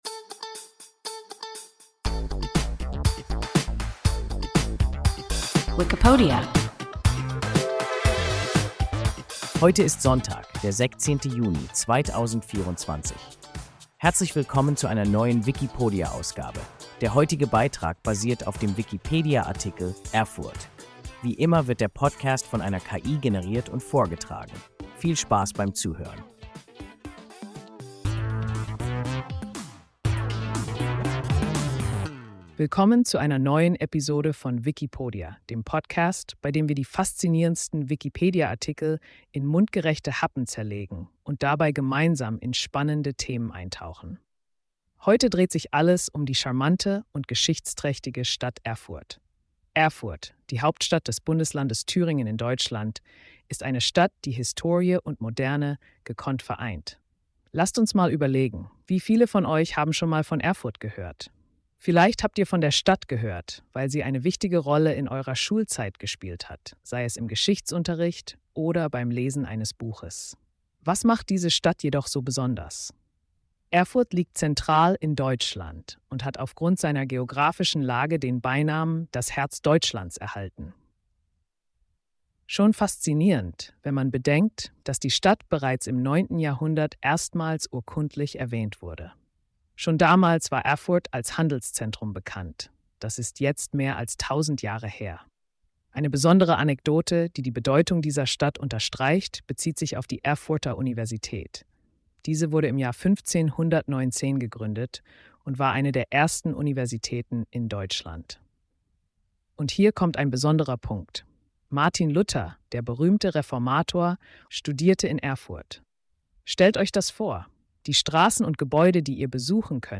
Erfurt – WIKIPODIA – ein KI Podcast